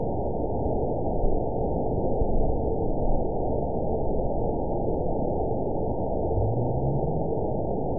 event 910561 date 01/22/22 time 05:12:56 GMT (3 years, 4 months ago) score 8.86 location TSS-AB02 detected by nrw target species NRW annotations +NRW Spectrogram: Frequency (kHz) vs. Time (s) audio not available .wav